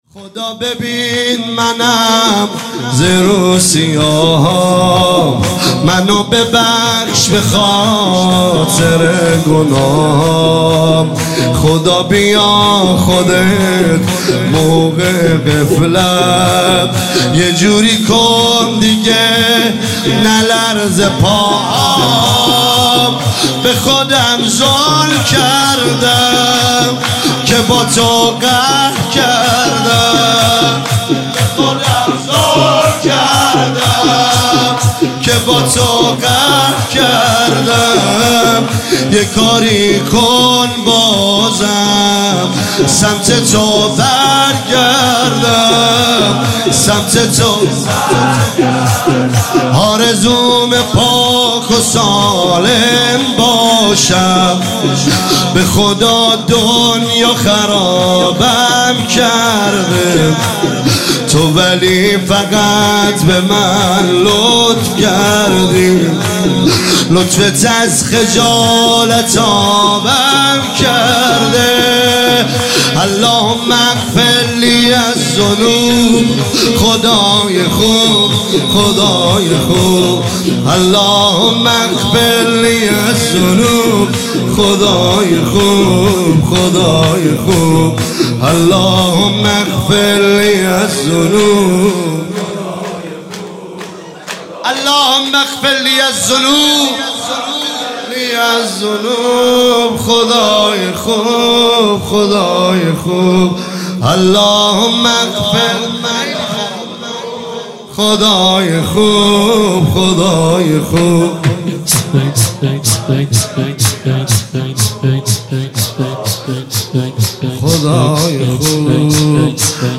عنوان شب ششم ماه مبارک رمضان ۱۳۹۸
مداح
شور